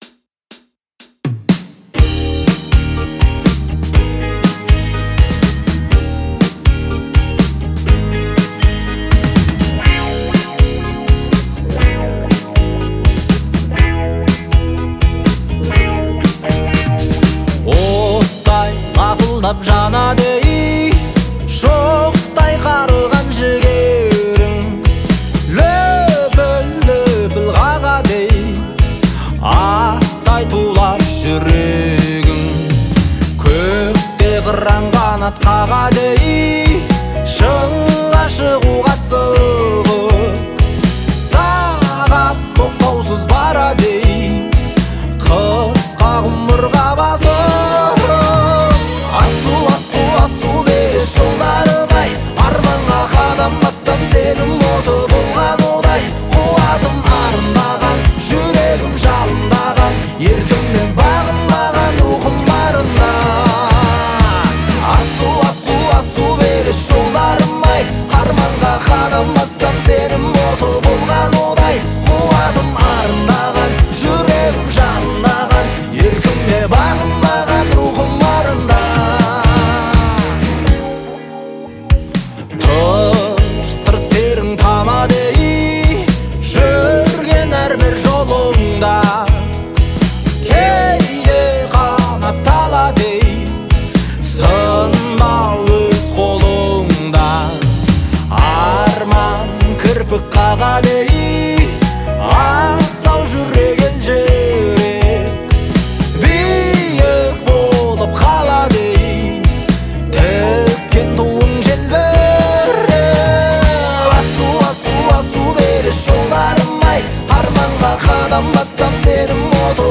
глубокий и выразительный вокал